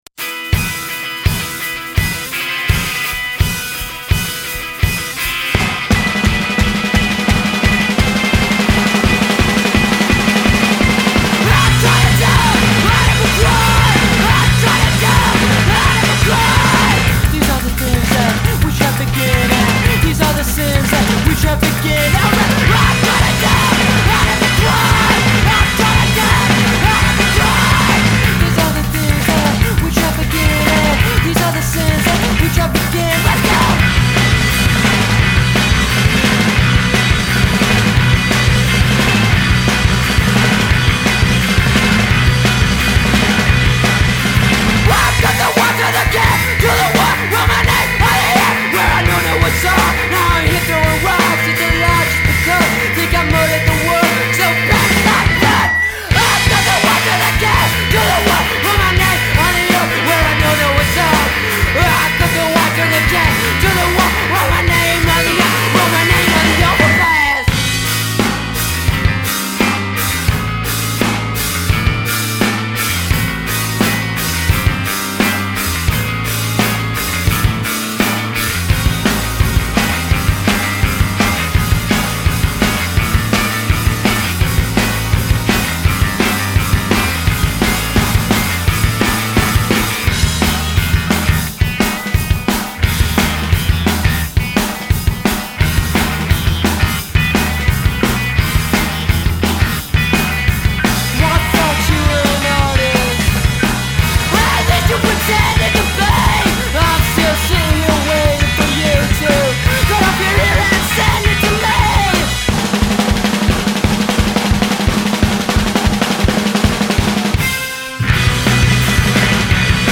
Vocals/Guitar
Bass
Drums
Hardcore , Punk Tags